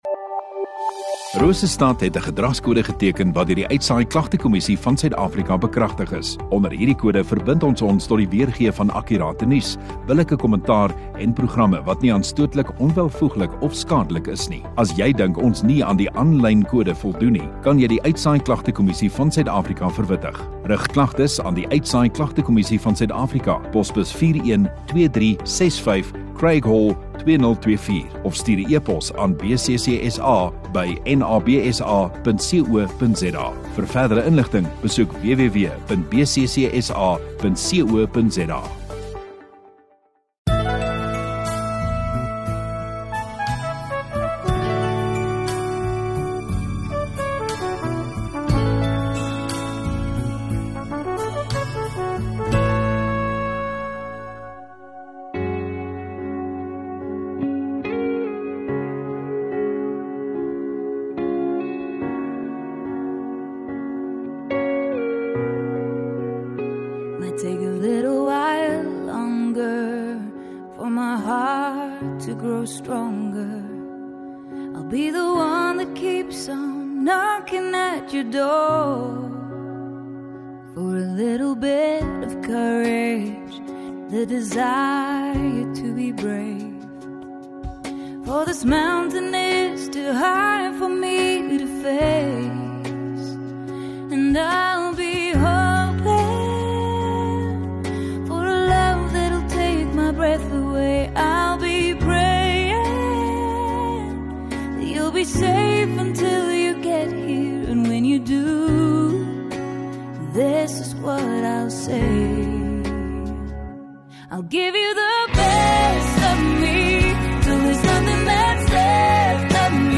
19 Jul Saterdag Oggenddiens